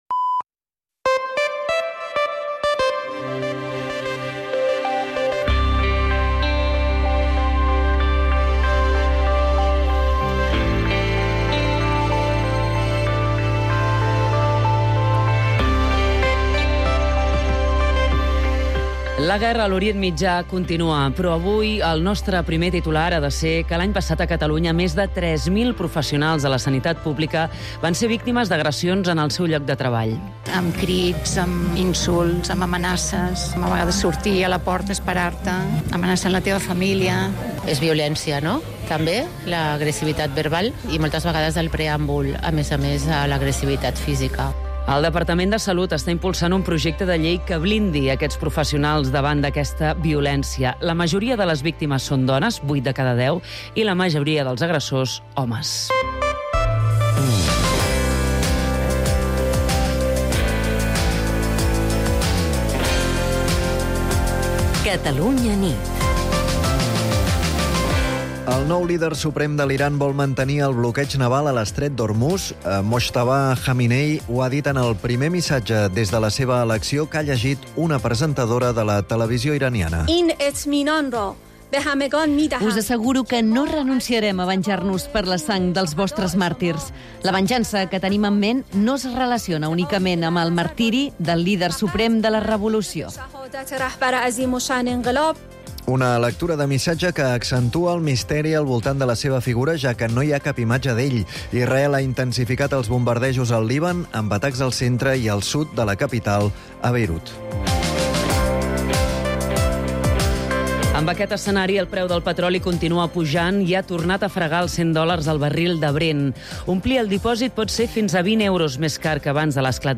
El compromís d’explicar tot el que passa i, sobretot, per què passa és la principal divisa del “Catalunya nit”, l’informatiu nocturn de Catalunya Ràdio, dirigit per Manel Alías i Agnès Marquès.